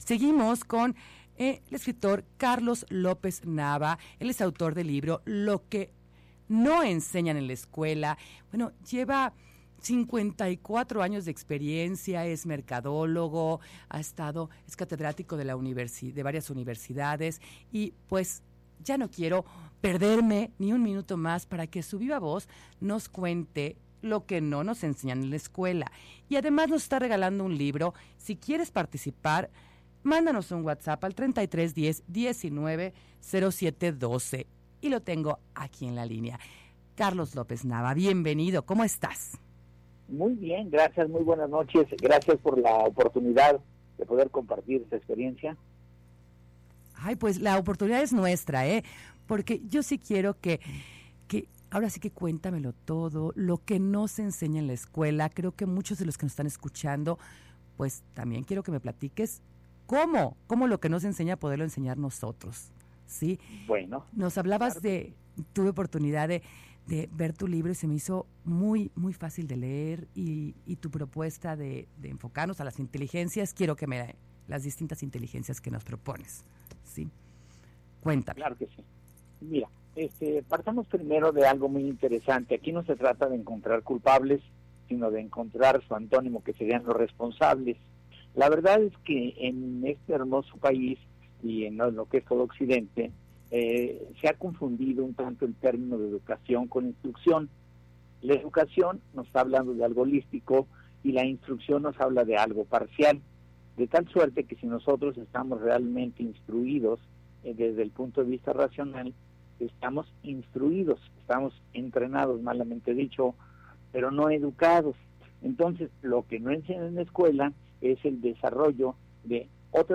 Entrevista Radio Mujer | Power Leadership Center
entrevista_radiomujer.mp3